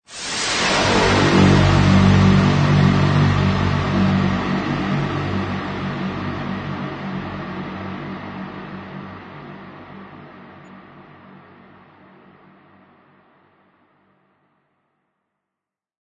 这是一个可以立即编排巨大的声音体验，激发戏剧性情感，创造出令人陶醉的亚音速和欣快的音景并推动全方位动态的集合。
Apocalyptic-Riser-Vocalscape-Whoosh-02.mp3